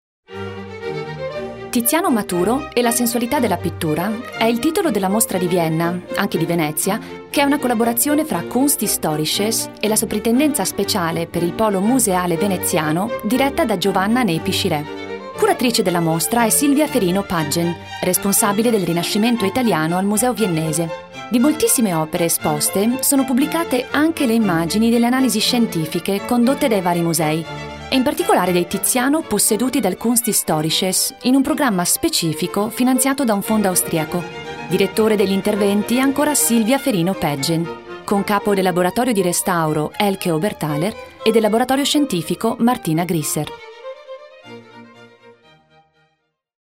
Italian. Interpreter, warm, clear, flirtatious to factual.